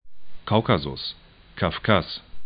Kaukasus 'kaukazʊs